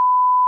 beep_right.mp3